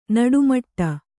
♪ naḍu maṭṭa